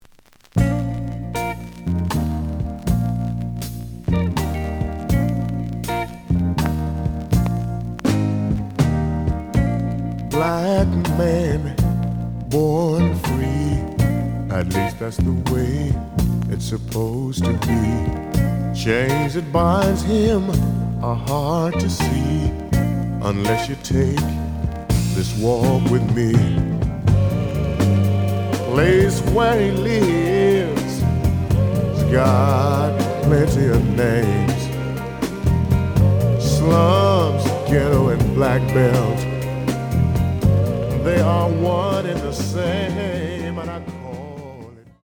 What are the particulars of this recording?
The audio sample is recorded from the actual item. Slight affect sound.